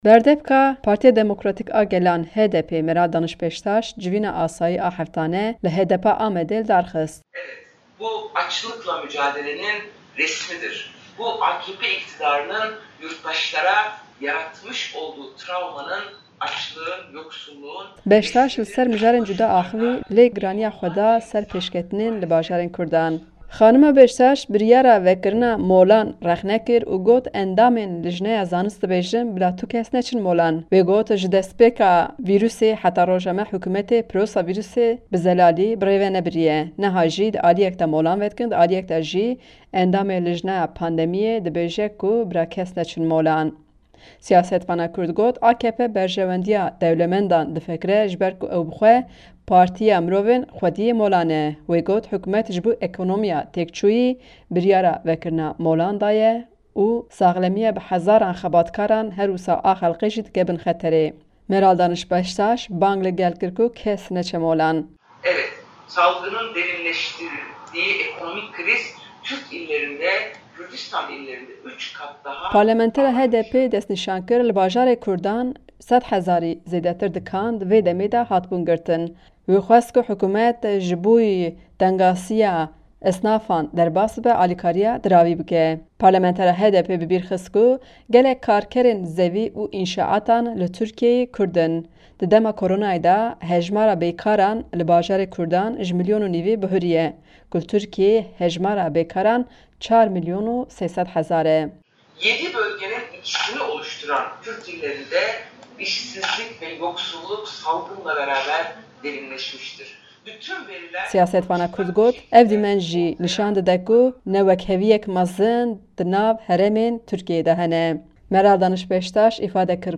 Cîgira Serokê HDP’ê Meral Daniş Beştaş, civîna asayî ya heftane li Amedê li dar xist û ser mijarên curbicir peyîvî.